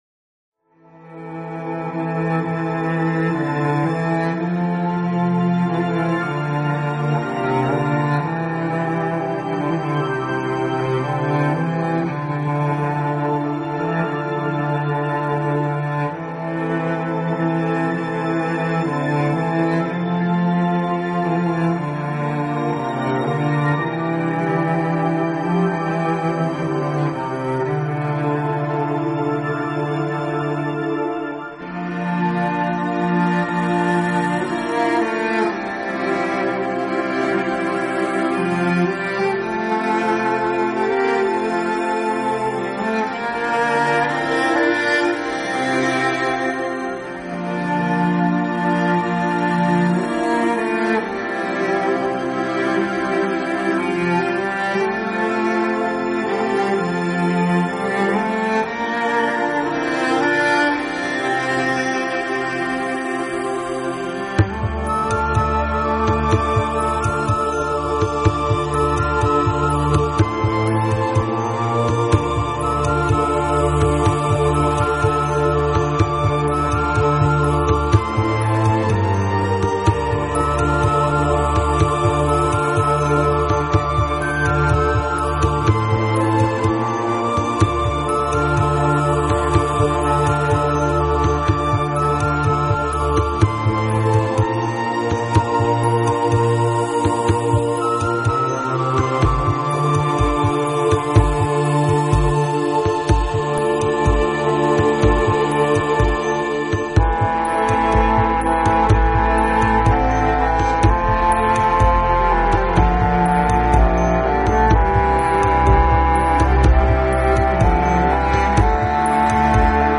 类型:General New Age
大提琴，像个经历风霜的老者娓娓述说关于过往辉煌故事；“平和之心”则是以来自